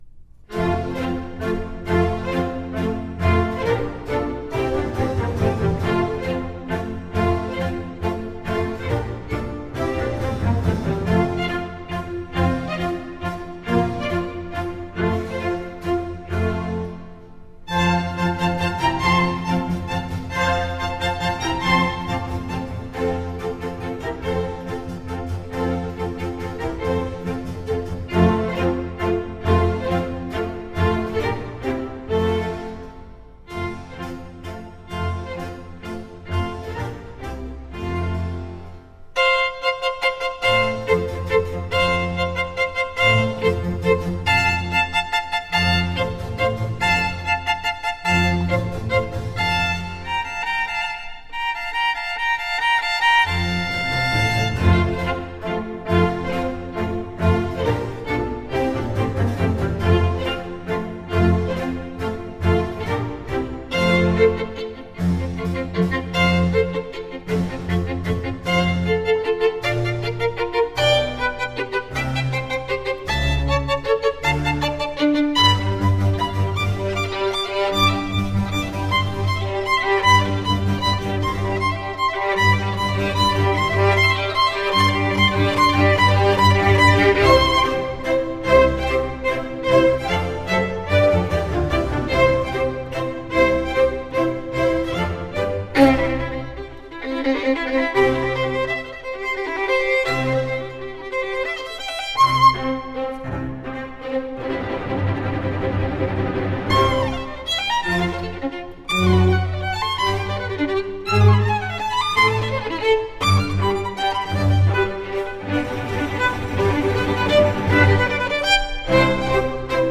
Classical, Baroque, Orchestral, Instrumental, Violin